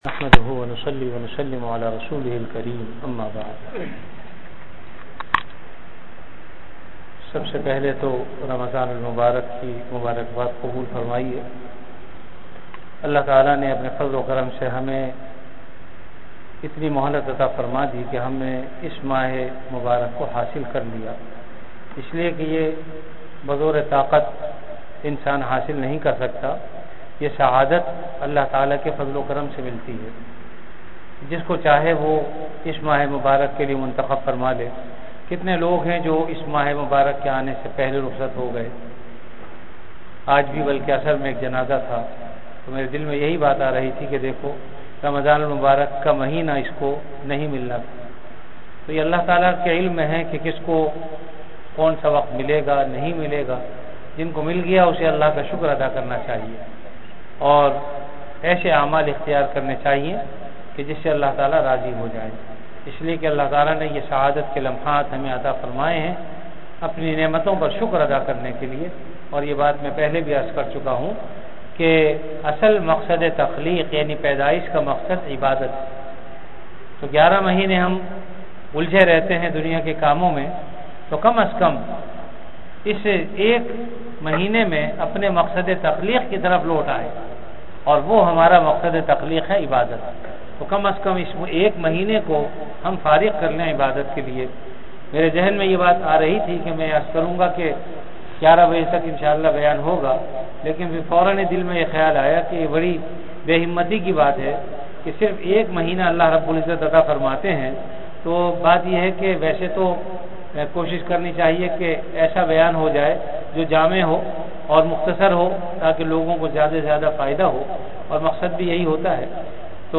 Ramadan - Taraweeh Bayan · Jamia Masjid Bait-ul-Mukkaram, Karachi
Taraweeh Bayan - 1 (Surah Fatiha, Surah Baqarah).mp3